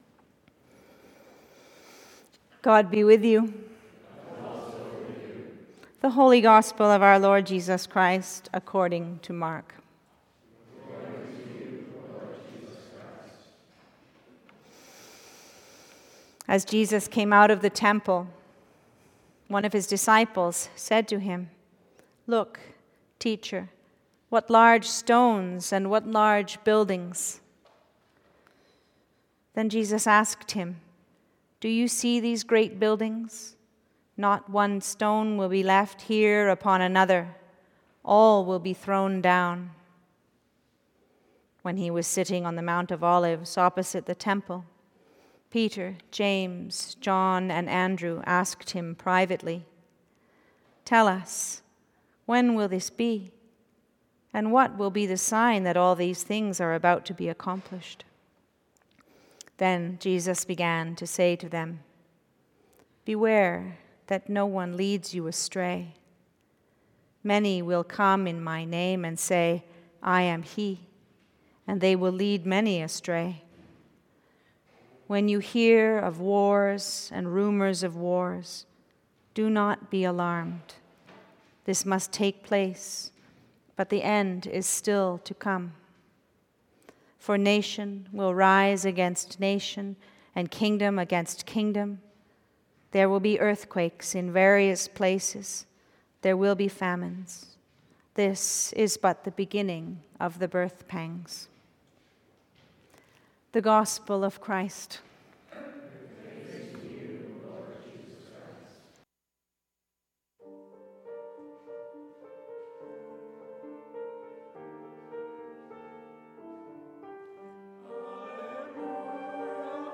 Sermon – First Sunday of Advent – November 30th, 2025